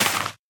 Minecraft Version Minecraft Version latest Latest Release | Latest Snapshot latest / assets / minecraft / sounds / block / sweet_berry_bush / break2.ogg Compare With Compare With Latest Release | Latest Snapshot